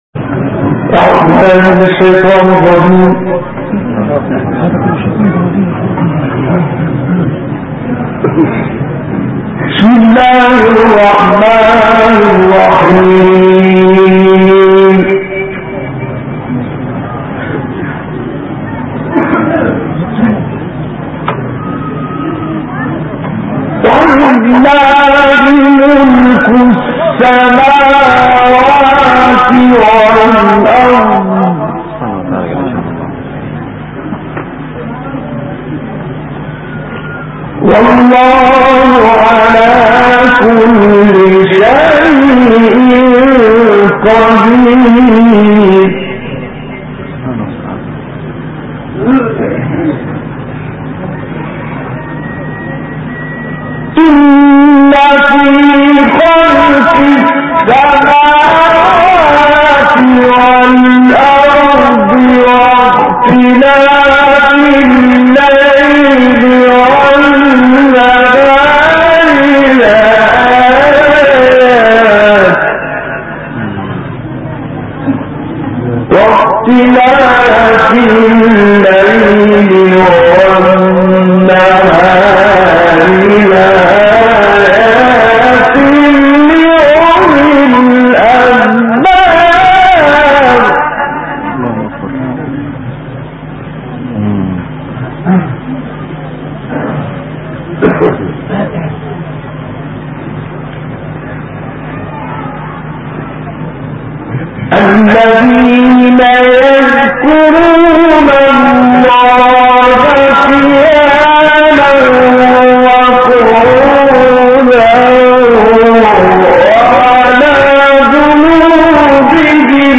باقة من التلاوات الخاشعة والنادرة للقارئ محمد عبد العزيز حصان 1